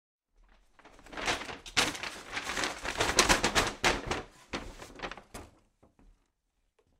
Download Paper sound effect for free.
Paper